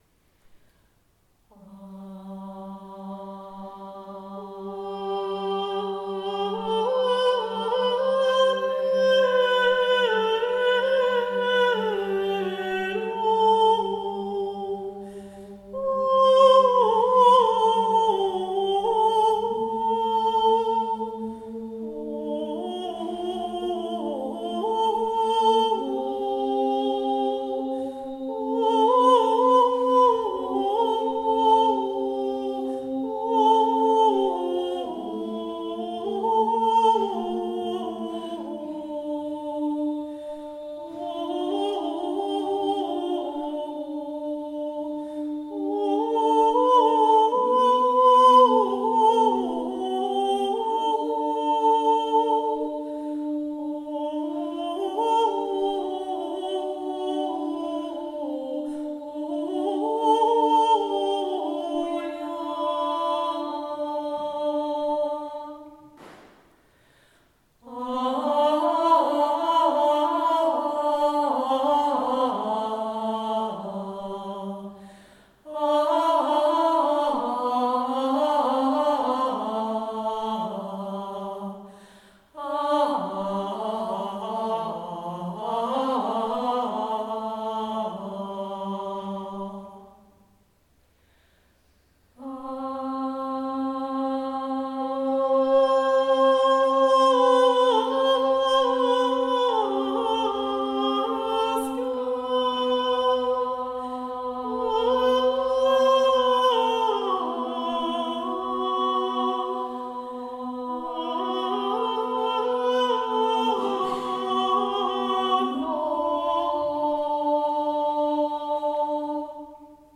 Ostermesse des Mittelalters (10.-12.Jh.)
Ein uralter Gesang aus der frühen Kirche, in allen christlichen Glaubensrichtungen tradiert.